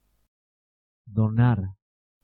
Ääntäminen
Vaihtoehtoiset kirjoitusmuodot gyve Synonyymit cede yield donate bend pass move guess estimate transfer flex predict resilience gift impart depict Ääntäminen : IPA : /ˈɡɪv/ US : IPA : [ɡɪv] UK brittisk engelska